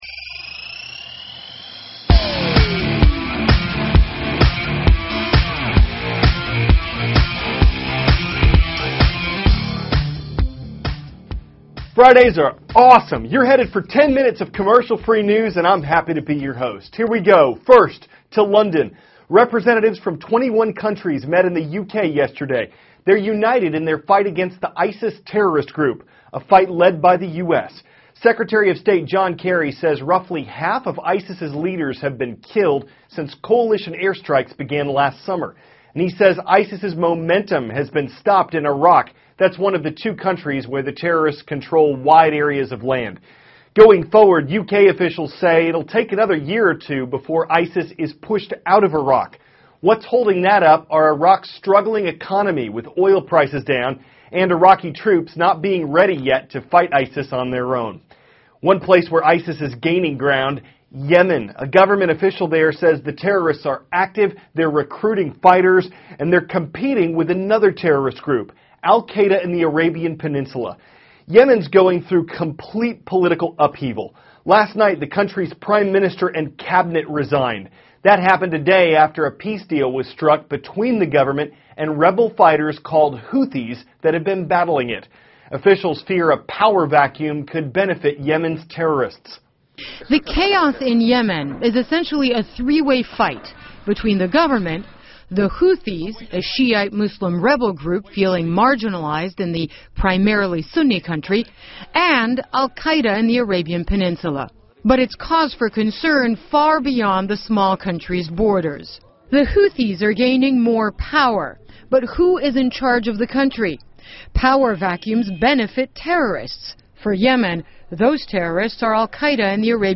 (cnn Student News) -- January 23, 2014